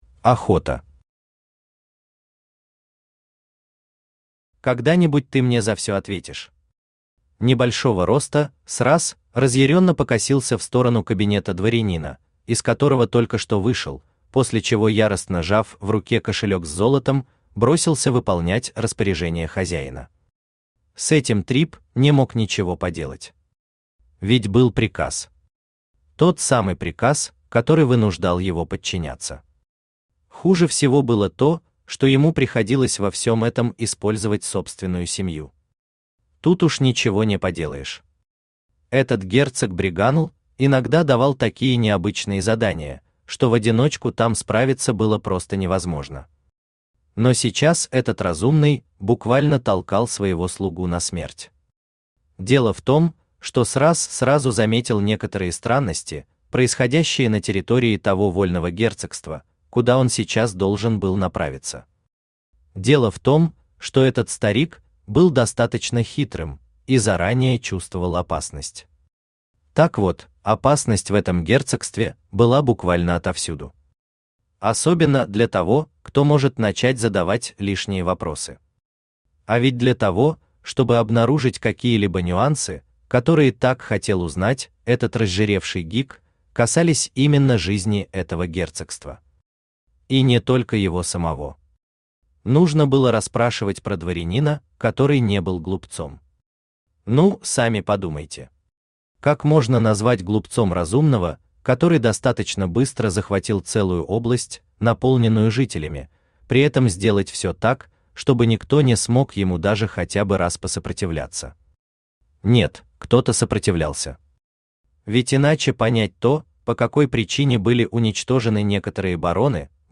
Долгий разговор Автор Хайдарали Усманов Читает аудиокнигу Авточтец ЛитРес.